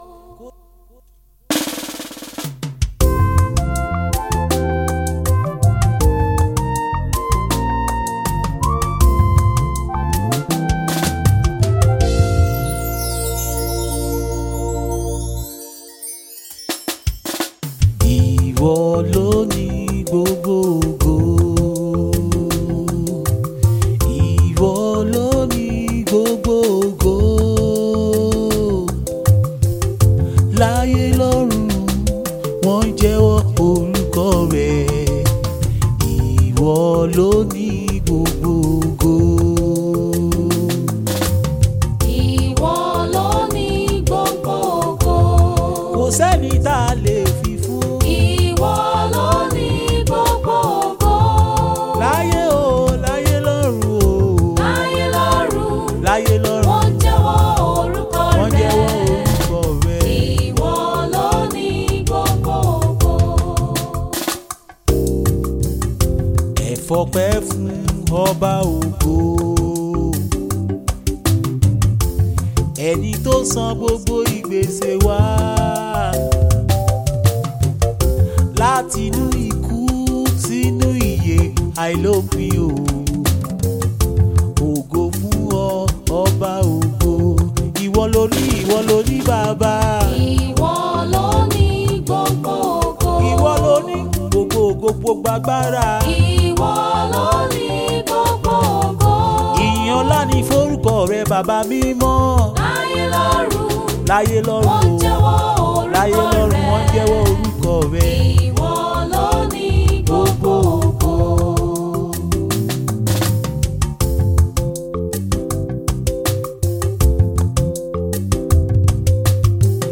fresh soul lifting music